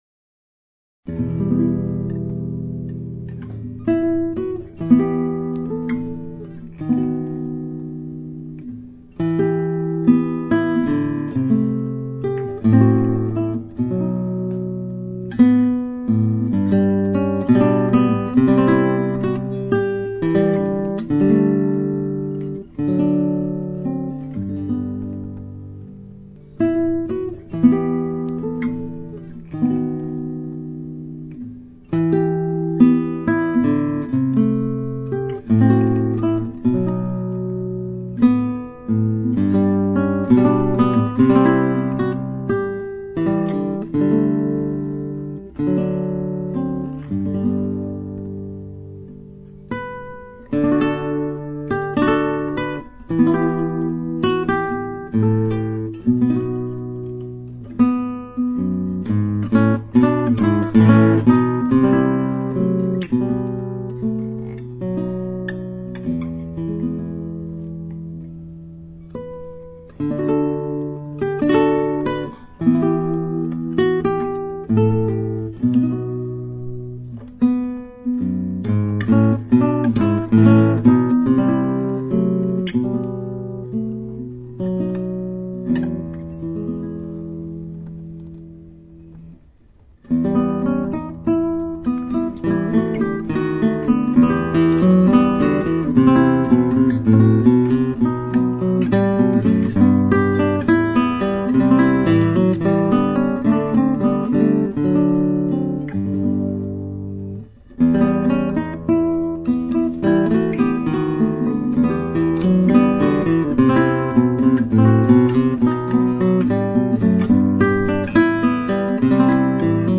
Classical Guitar